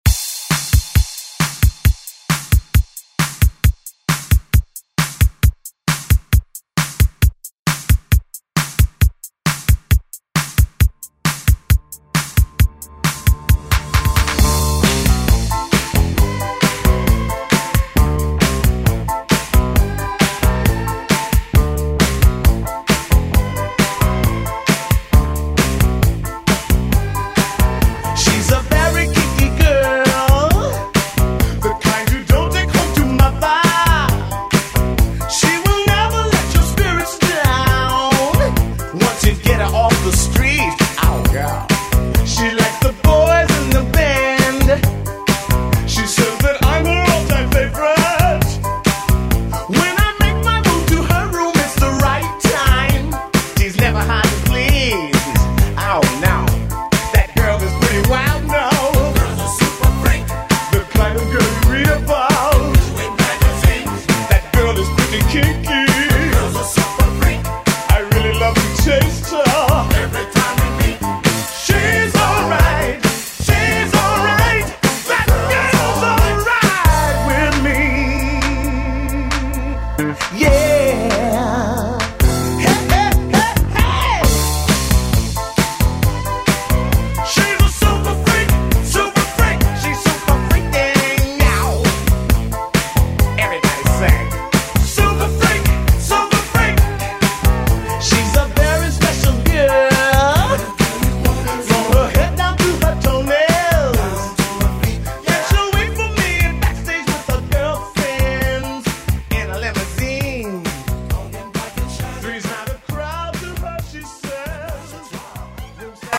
Electronic Dance House Music
Genre: 2000's
Clean BPM: 123